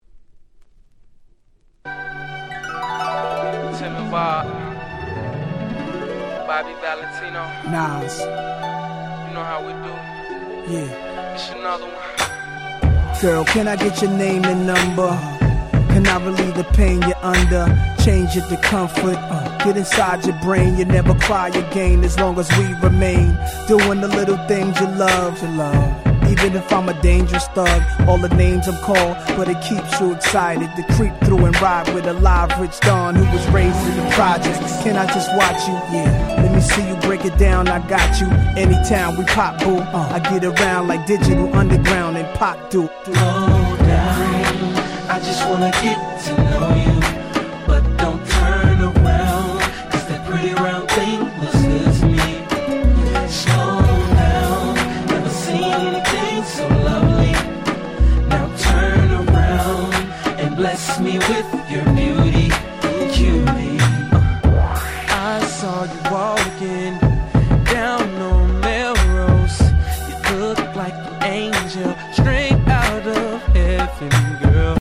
05' Smash Hit R&B !!